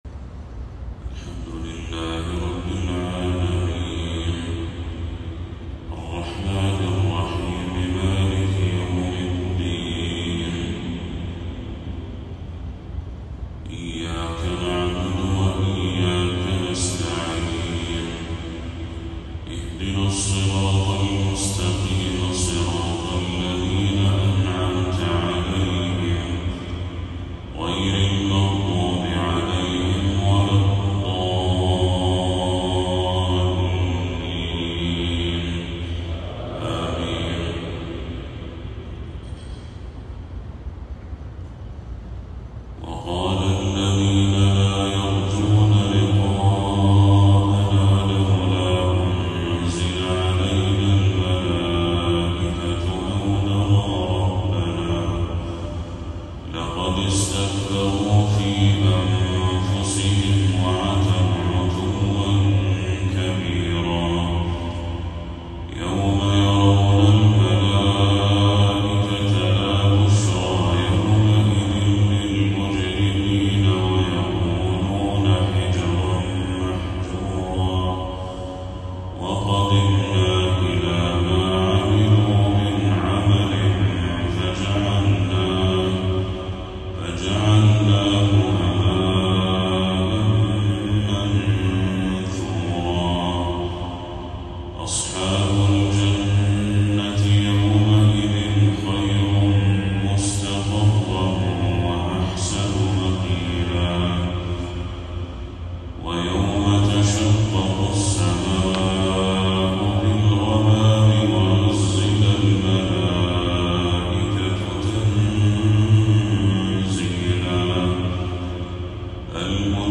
تلاوة مؤثرة من سورة الفرقان للشيخ بدر التركي | فجر 22 صفر 1446هـ > 1446هـ > تلاوات الشيخ بدر التركي > المزيد - تلاوات الحرمين